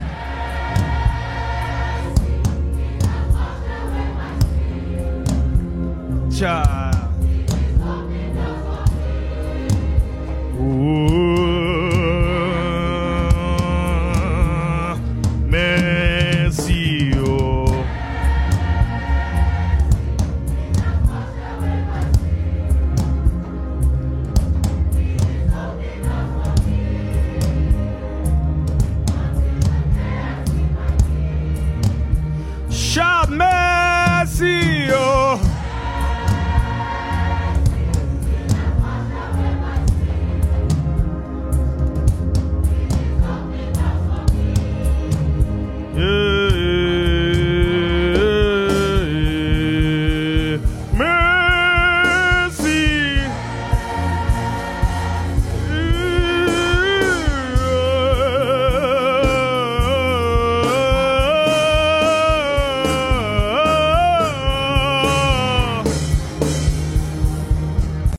Nigeria Gospel Music
Contemporary Christian music